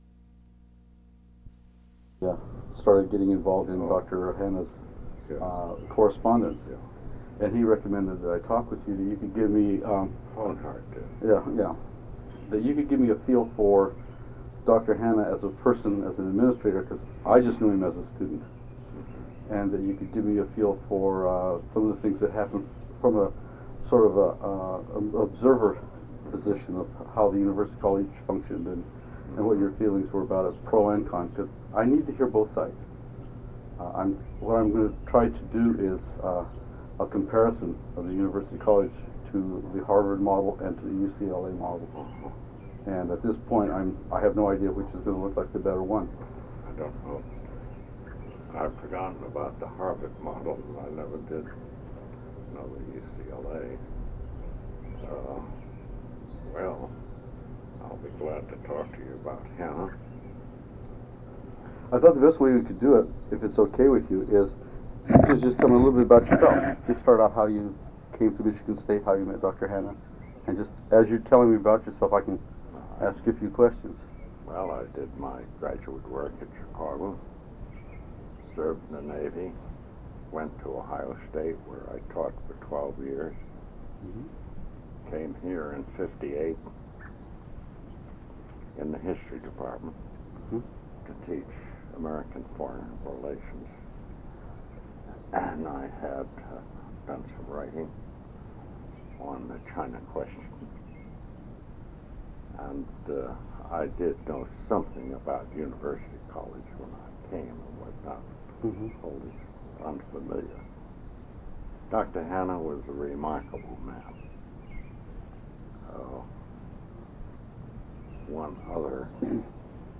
Interview
Date: June 7, 1990 Format: Audio/mp3 Original Format: Audio cassette tape Resource Identifier: A008657 Collection Number: UA 10.3.156 Language: English Rights Management: Educational use only, no other permissions given.